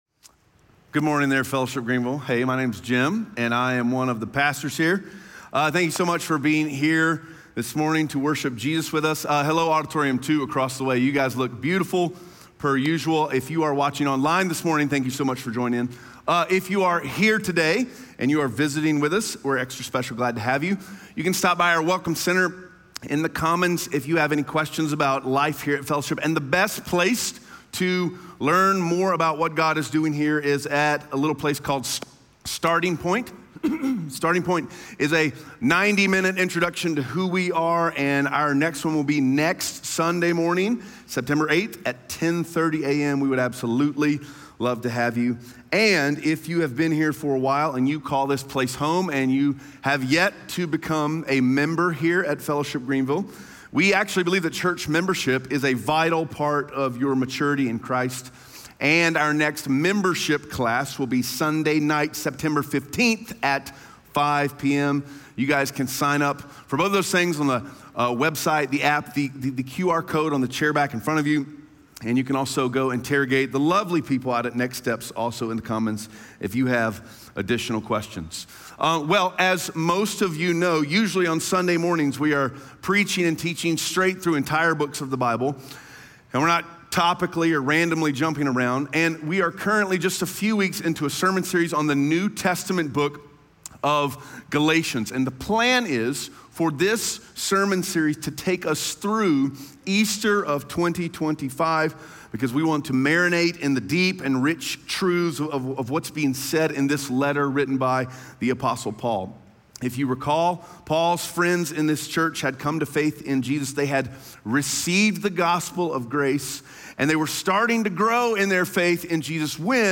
Galatians 1:11-24 Audio Sermon Notes (PDF) Ask a Question SERMON SUMMARY How do you interpret your own life?